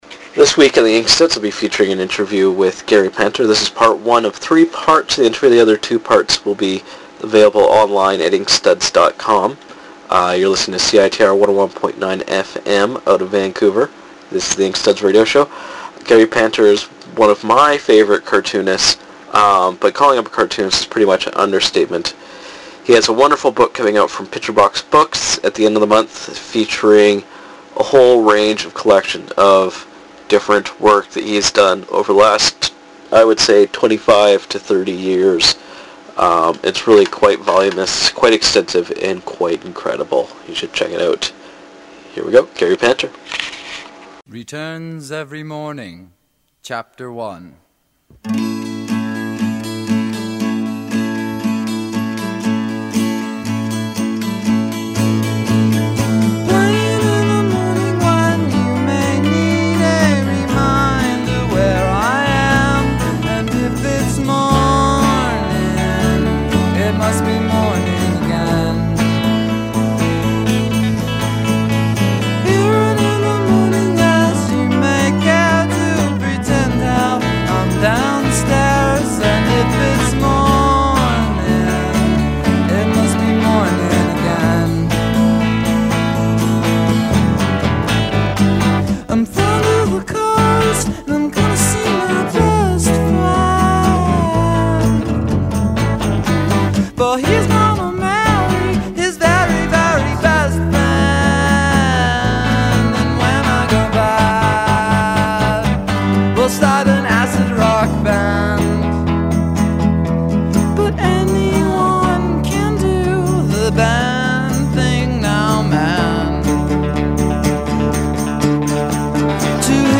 Here it is folks, part one of my interview with Gary Panter.